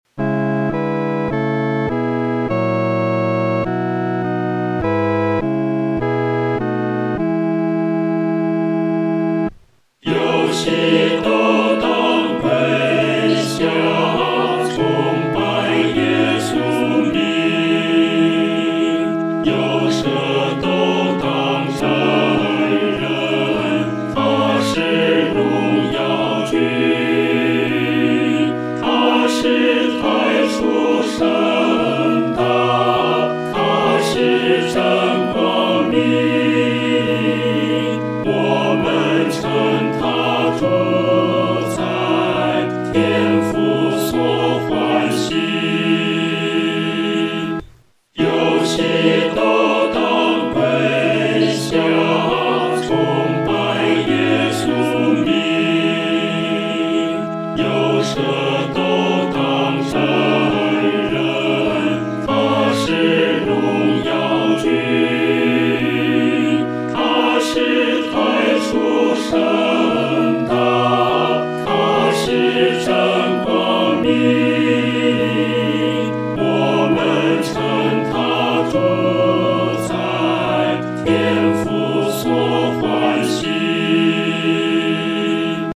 合唱
四声
这首诗歌宜用中庸的速度来弹唱。